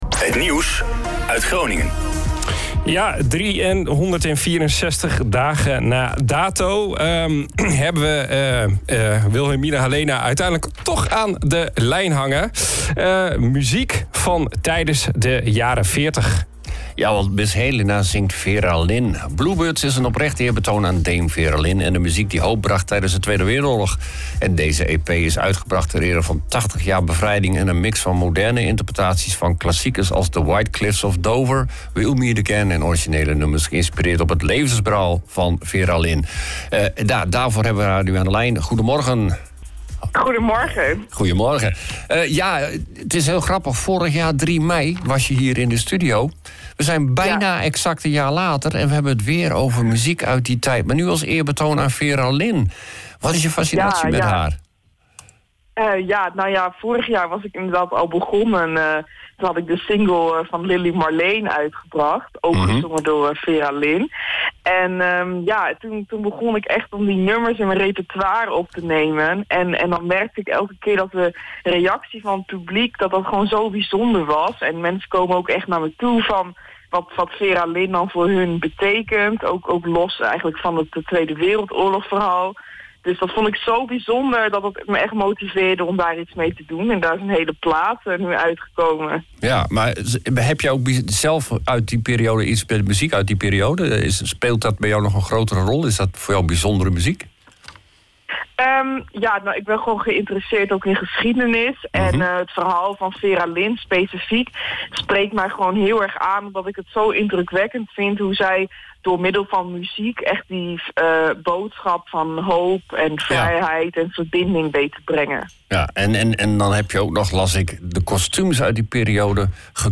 Ze sprak er vrijdagochtend over in de Ochtendshow:
Interview